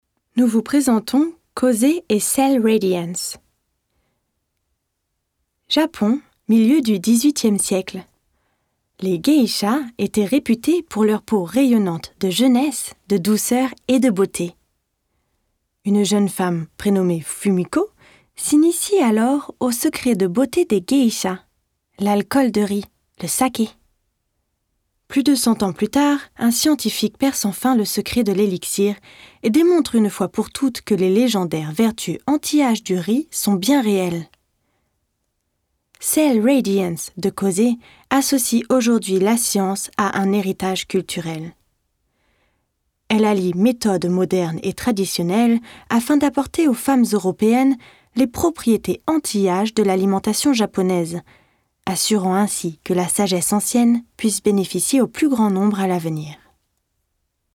Native French Speaker Französische Sprecherin Französische Schauspielerin / French Actress
Sprechprobe: Sonstiges (Muttersprache):